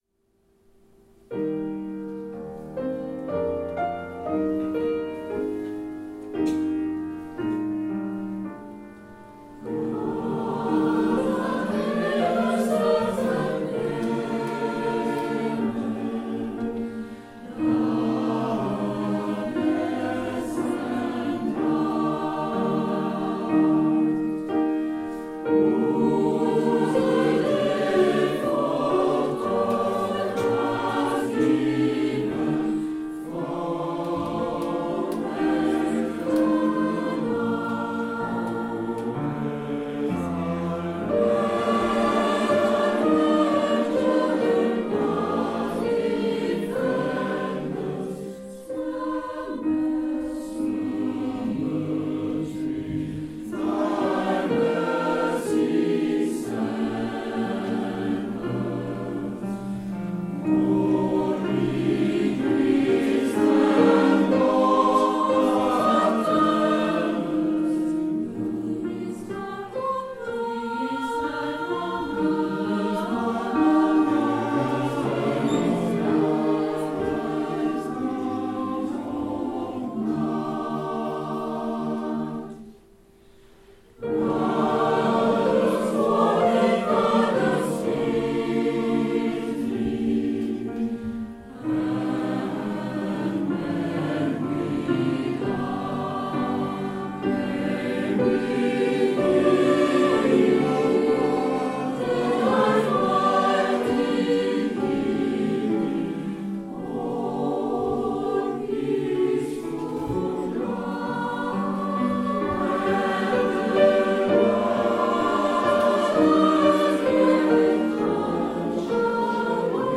Concert Salle de la Tour de Voisins le btx 9 juin 2013
Enregistrement du concert de la salle de la tour